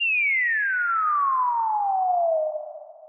MB Drop Effect (2).wav